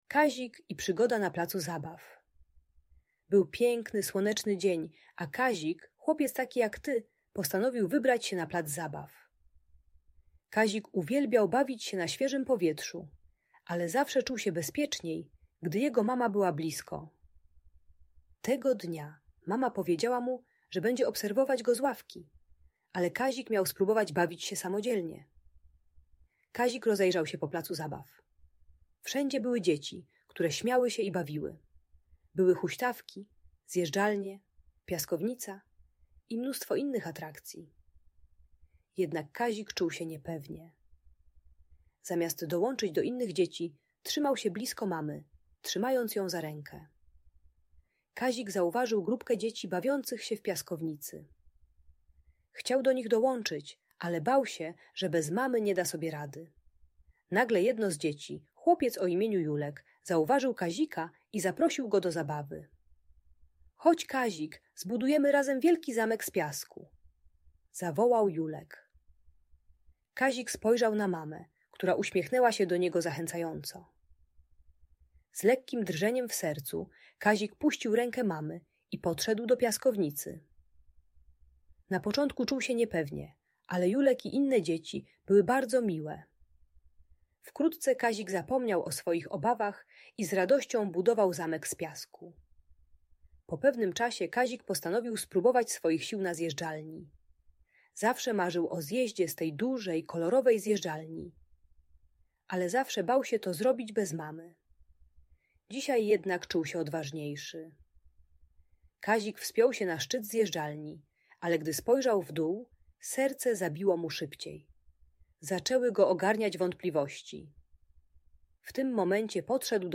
Kazik i Przygoda na Placu Zabaw - Audiobajka